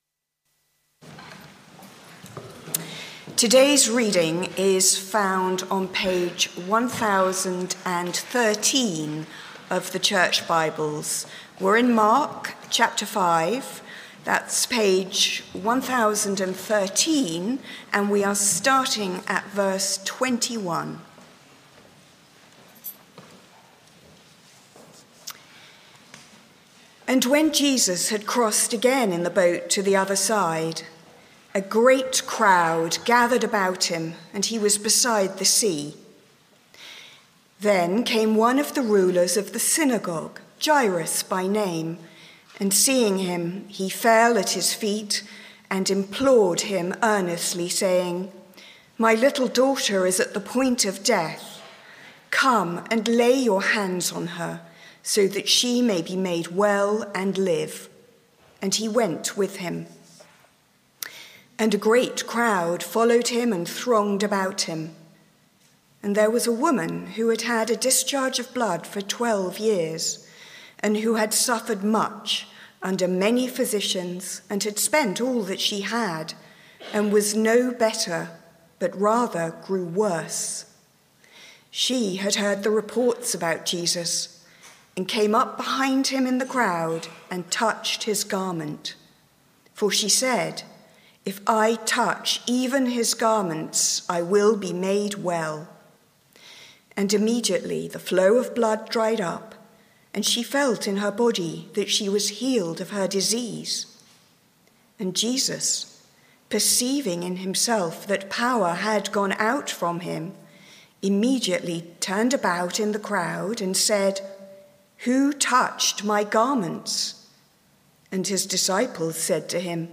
Mark 1-6 Sermon - Audio Only Search media library...